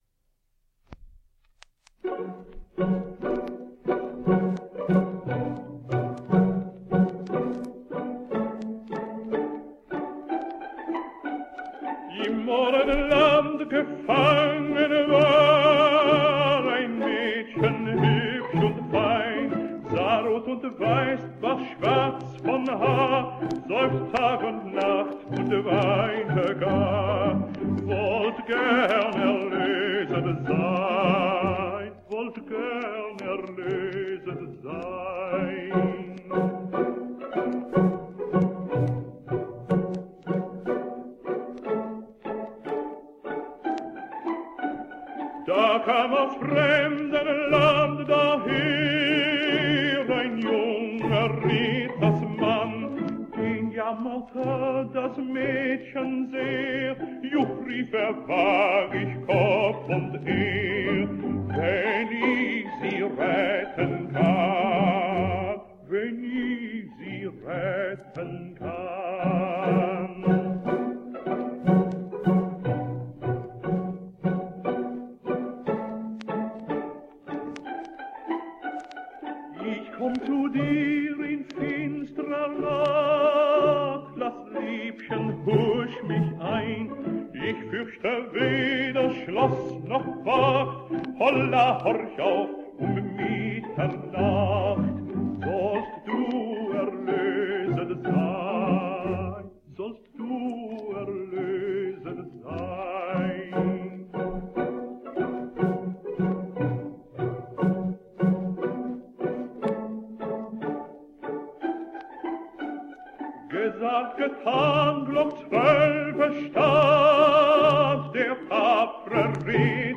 Danish tenor.
And a serenade from act three, sung by Pedrillo, to his love, Prince Tansa’s English maid.